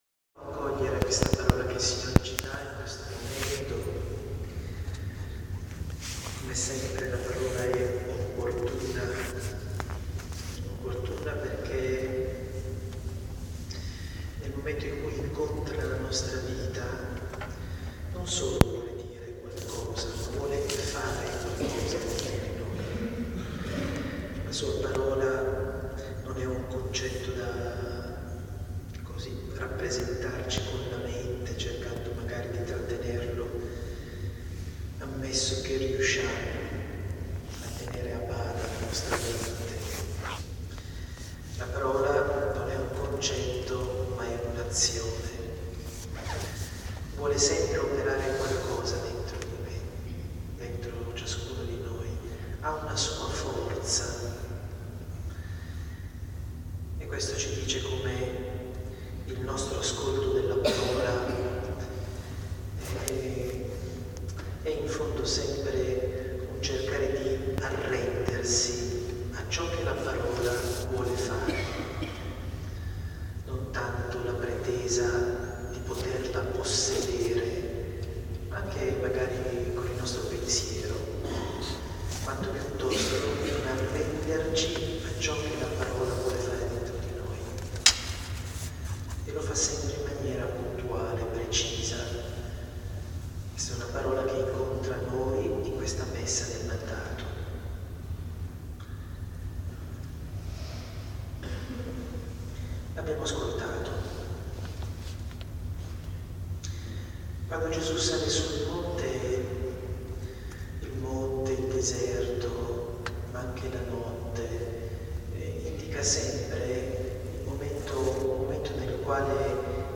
Ascolta l’omelia del Vescovo Vittorio Viola registratas durante la celebrazione della Messa del mandato tenutasi a Novi Ligure.
MandatoOmelia.mp3